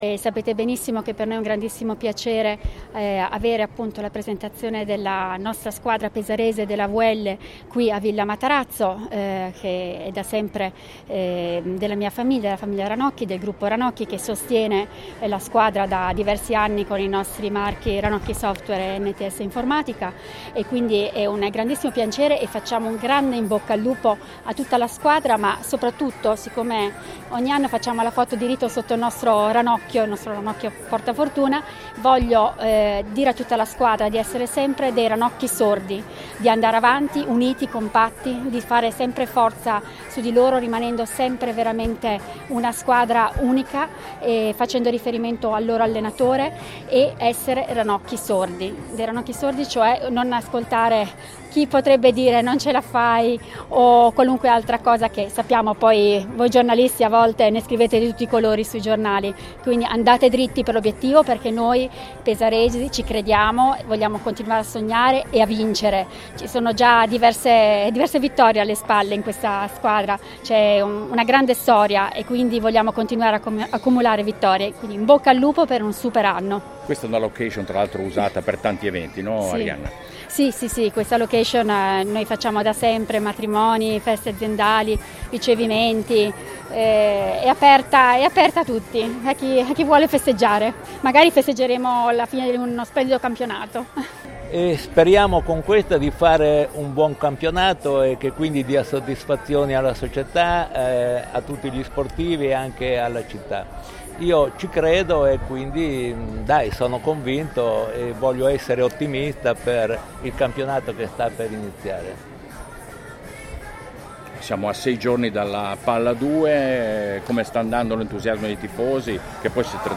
Presentato nella splendida Villa Matarazzo a Fanano di Gradara, lo Staff e i Giocatori della Carpegna Prosciutto Basket Pesaro.